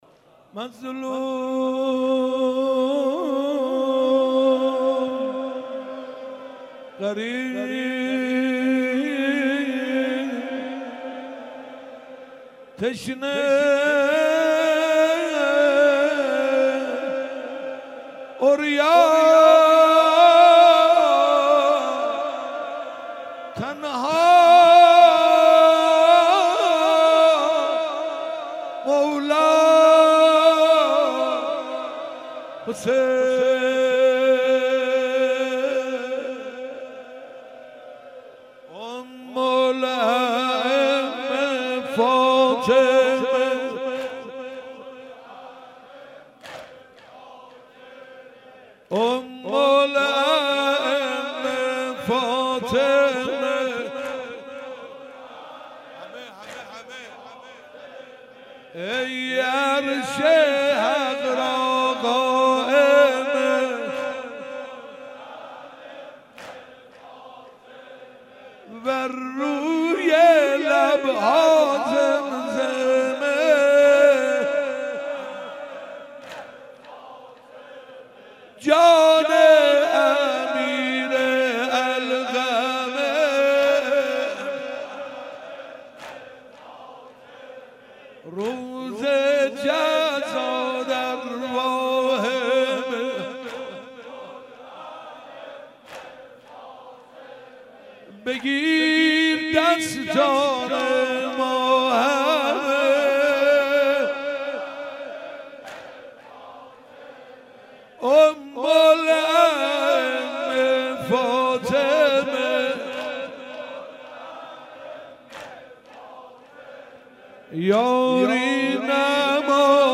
بیست و هشتم محرم 96 - هیئت فدائیان ولایت - مظلوم